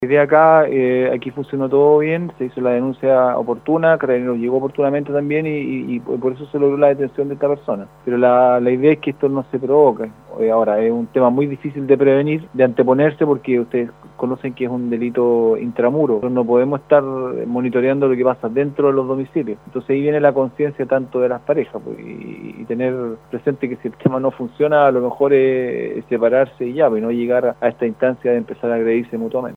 El oficial señaló que la comunidad debe estar atenta a estas situaciones de manera que desde las instancias respectivas, se puedan prevenir mayores afectaciones a las personas.